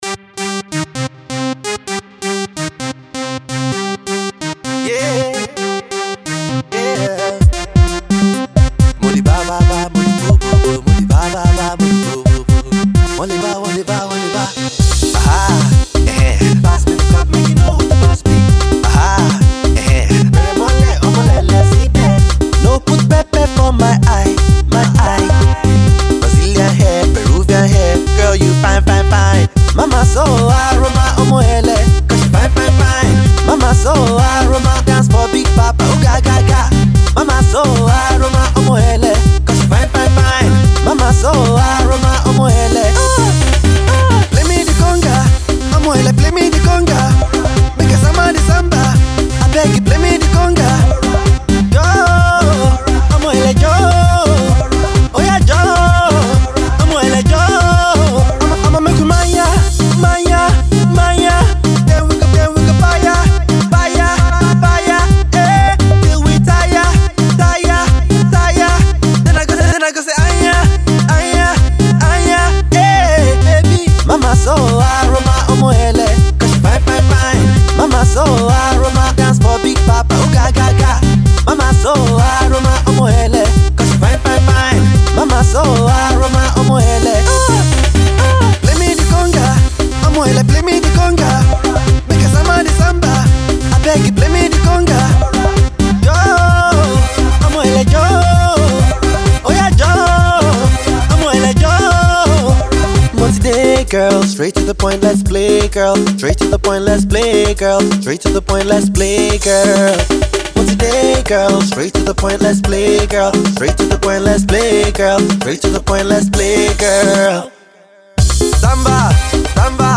new club banger
Put on your dancing shoes and get ready to dance